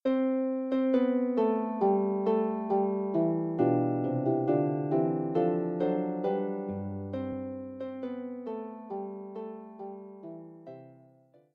arranged for solo lever or pedal harp